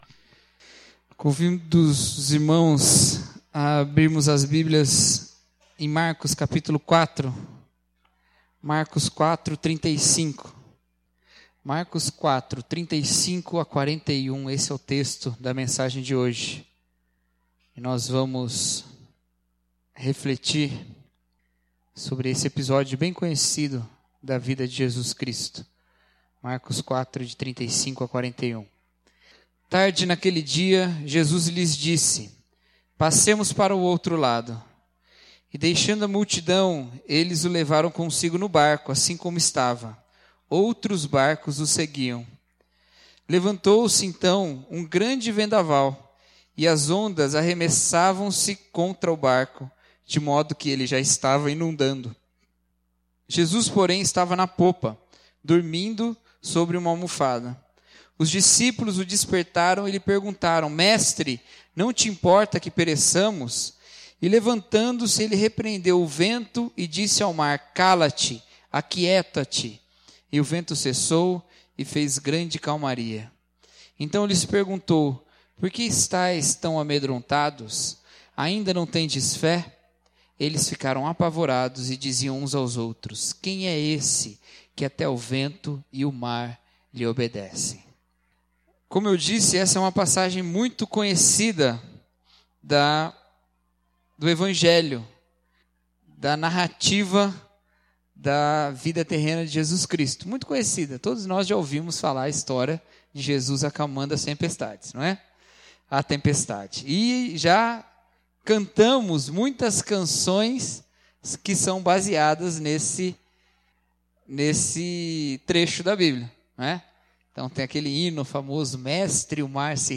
Mensagem: Jesus Acalma a Tempestade